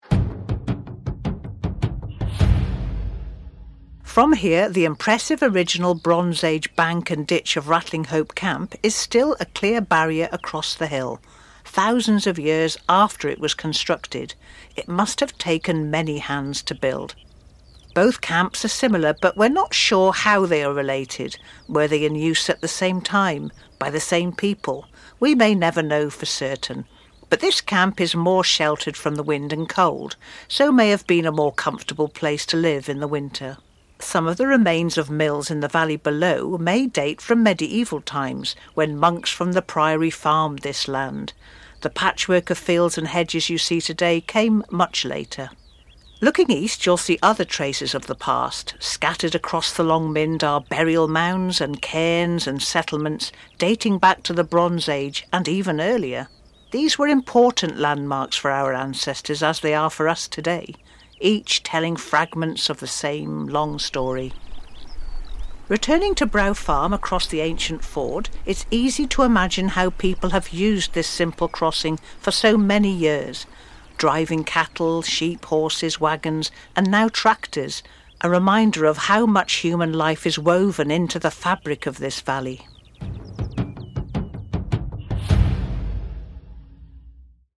Brow Farm Hillforts Walk & Audio Guide